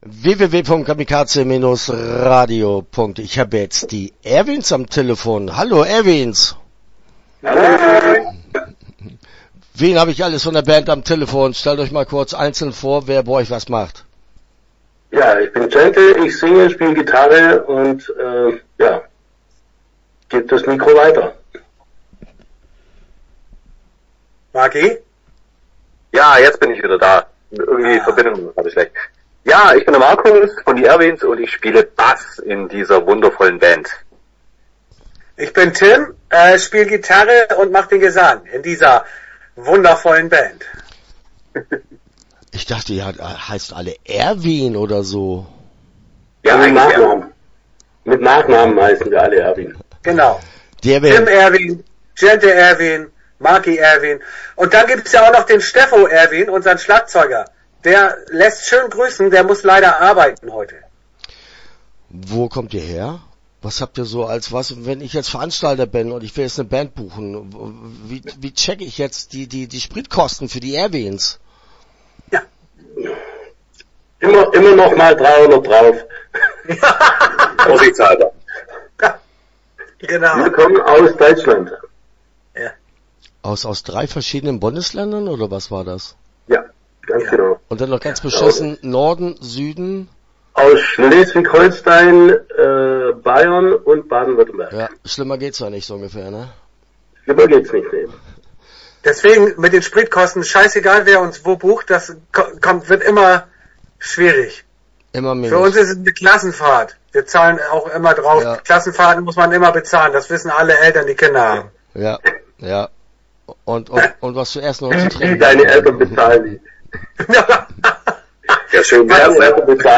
Start » Interviews » Die Erwins